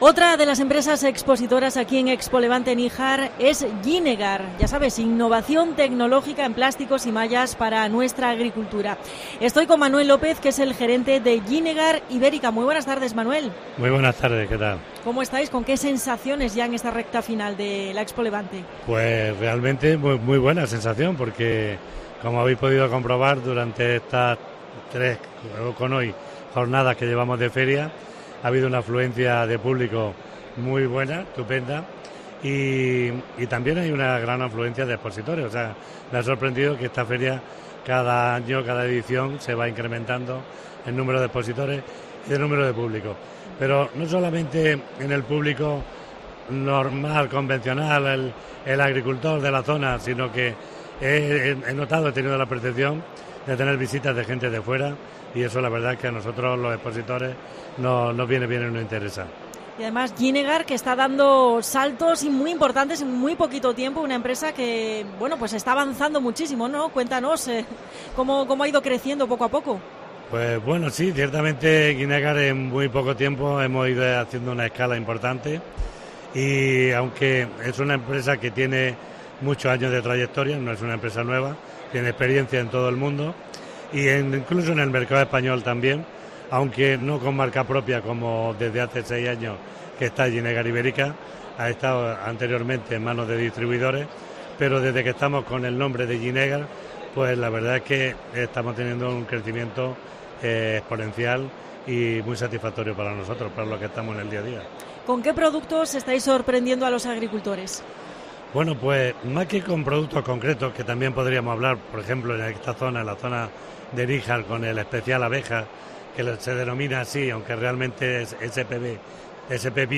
AUDIO: Especial COPE Almería desde ExpoLevante en Níjar.